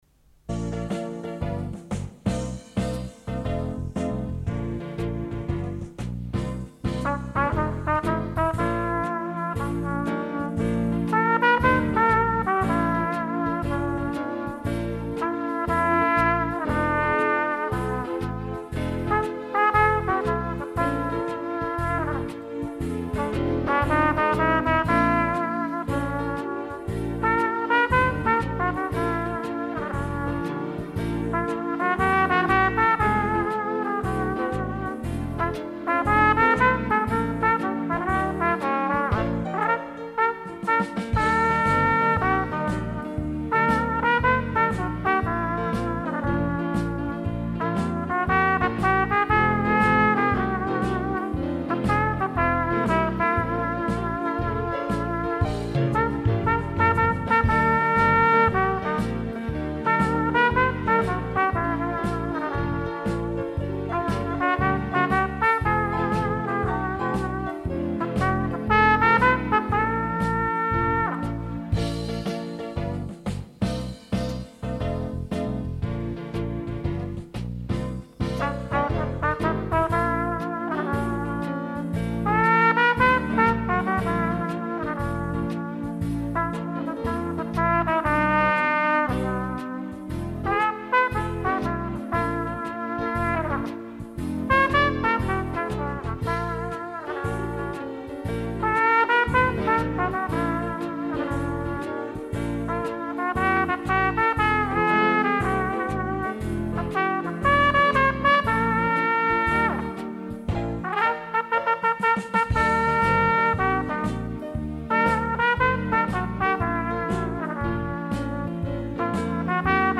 Kategorie: Instrumental